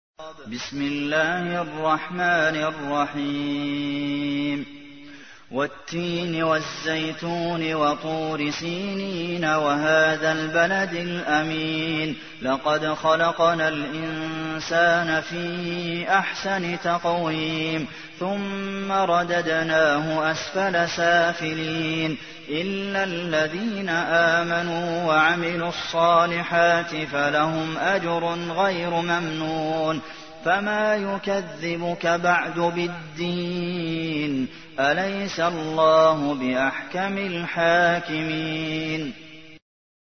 تحميل : 95. سورة التين / القارئ عبد المحسن قاسم / القرآن الكريم / موقع يا حسين